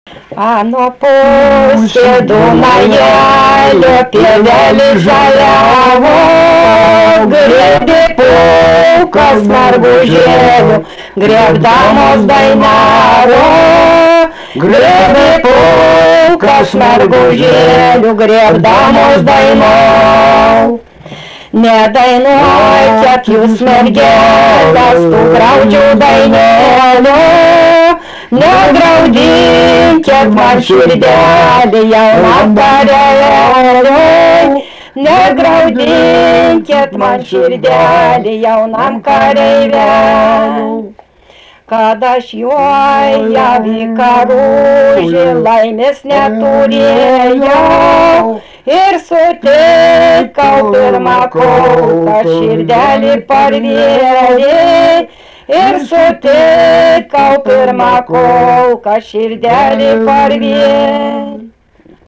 daina, vaikų